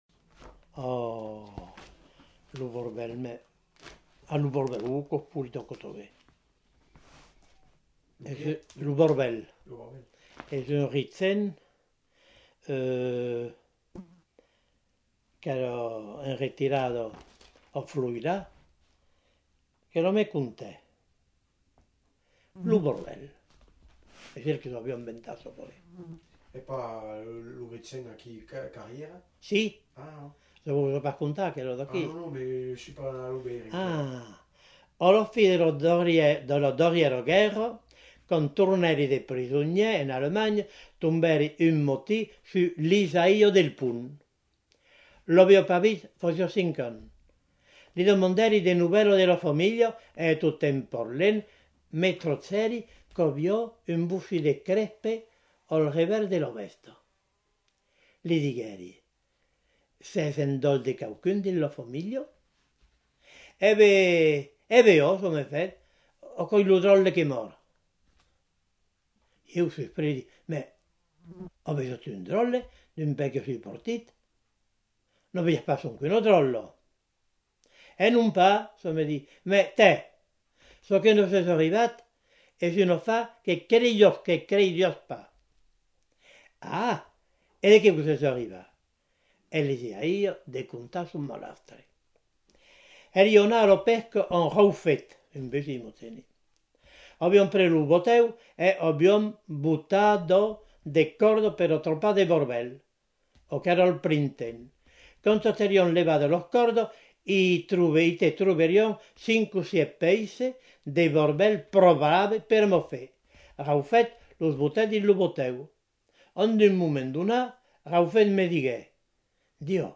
Lieu : Rocamadour
Genre : conte-légende-récit
Type de voix : voix d'homme
Production du son : parlé
Classification : conte facétieux